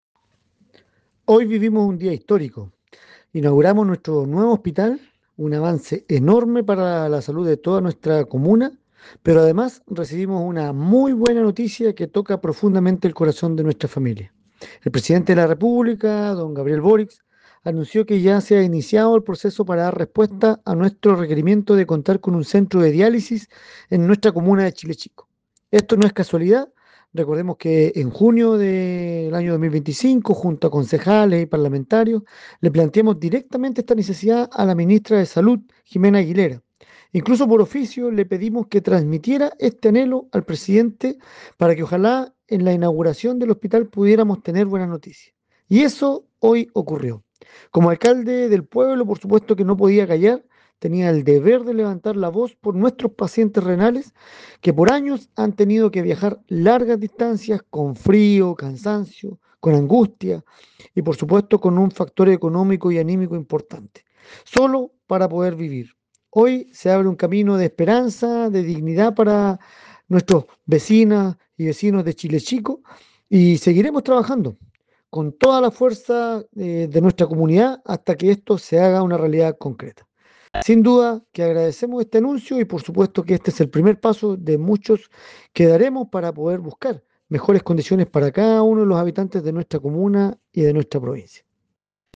Alcalde de Chile Chico Ariel Keim